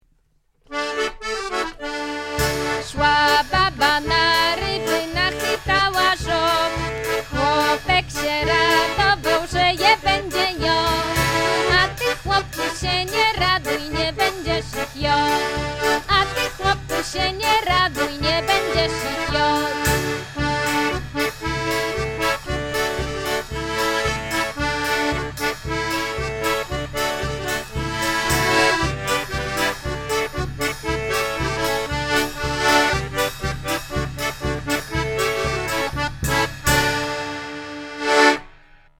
Utwory zostały zanotowane przez Adolfa Dygacza a opracowane są przez „Kapelę Fedaków”.